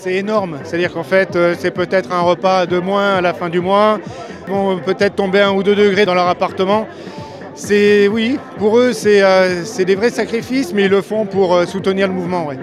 Étudiants, fonctionnaires, retraités, ouvriers : Ils étaient environ un millier à s’être réunis hier pour la manifestation à Mende dans le cadre du mouvement national de grève du 18 septembre.